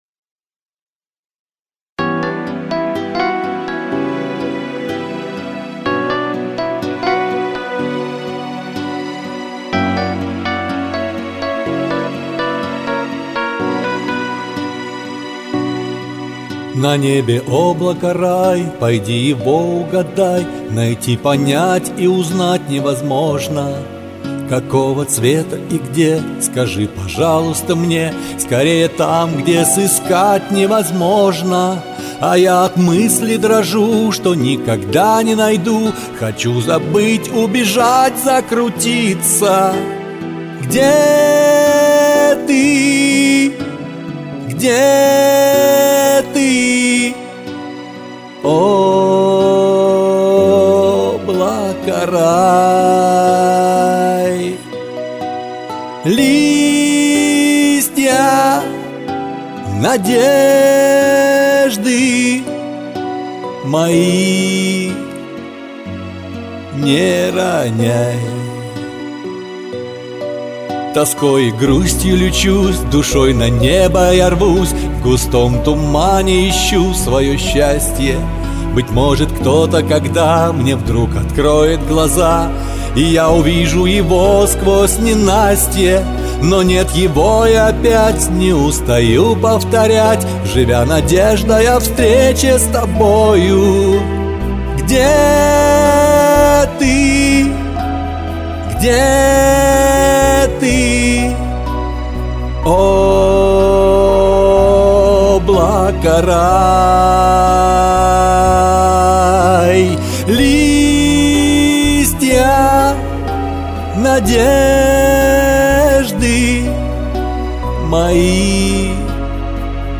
Минус скушноват, однако.